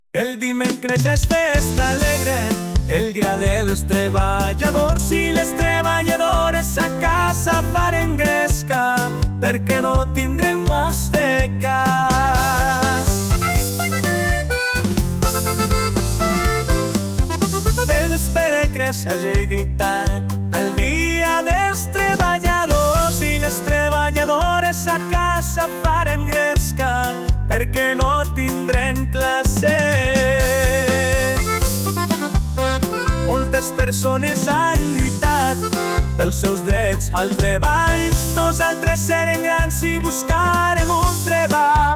Música generada per IA